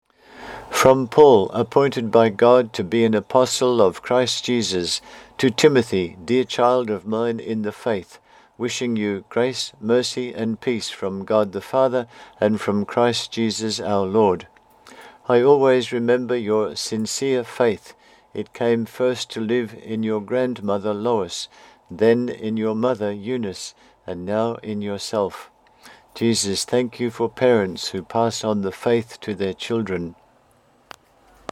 The Book Blog:   4 readings + recordings
My recording of this reading